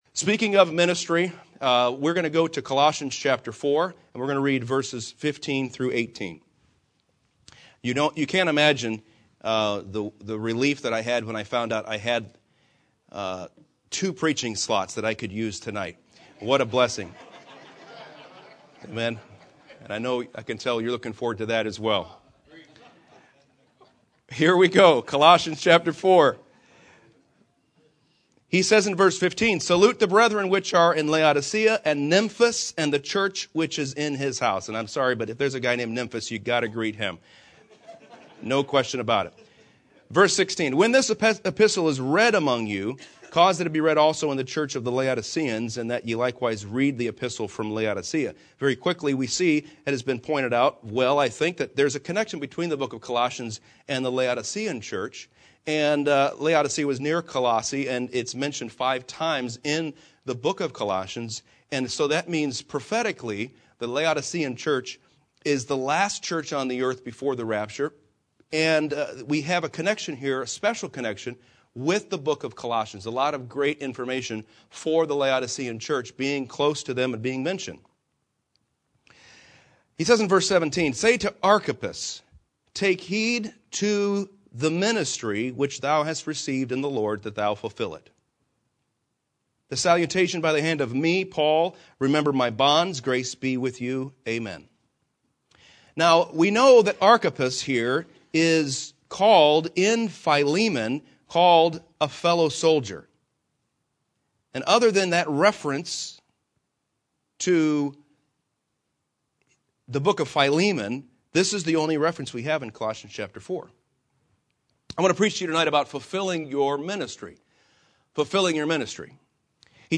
Preachers Conference